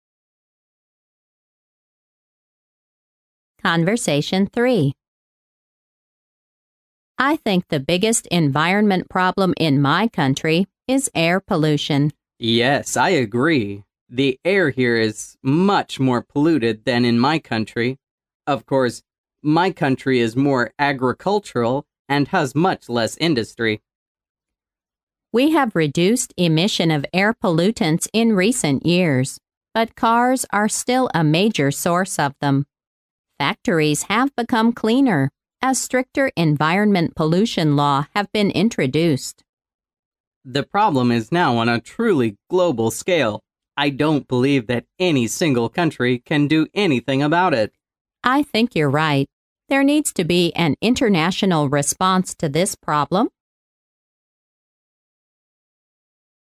潮流英语情景对话张口就来Unit18：空气污染mp3